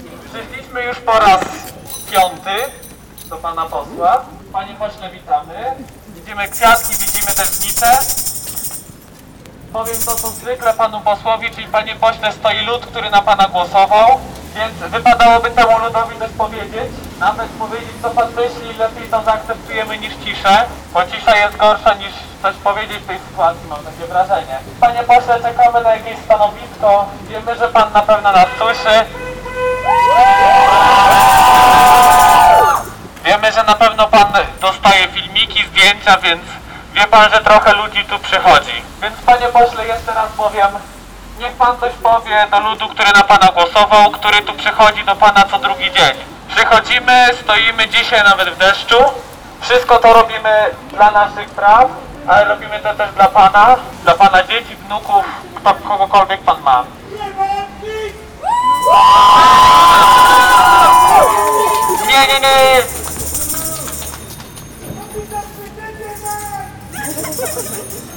Mniej liczne grono uczestników – może z powodu deszczu – przeszło ulicą Noniewicza i tak zwanym przechodniakiem do ulicy Kościuszki, by tam przy dwóch biurach poselskich posłów PiS wyrazić swoje zdanie w kwestii, która kazała także suwalczanom protestować.